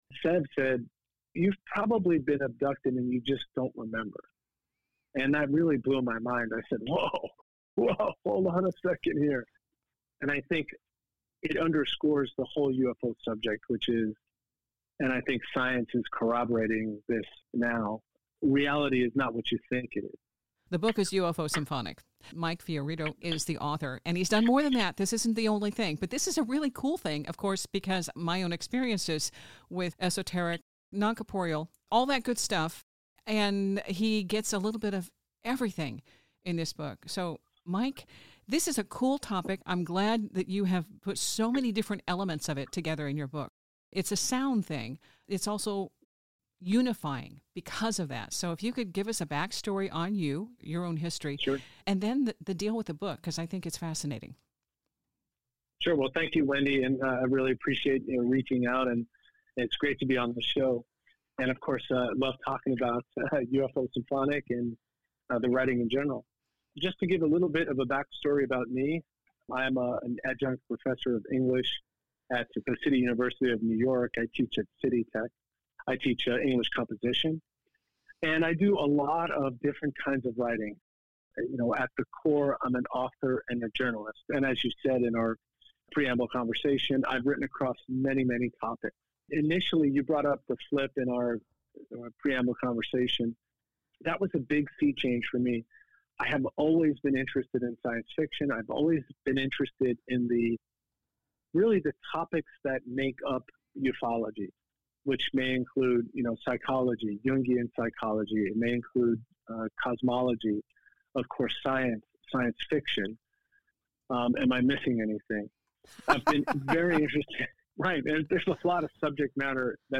From flutes to fairies, this conversation hits all the right notes. Aimed at more than just the UFO-curious, this book is an invitation to consider how music and meaning entwine across dimensions.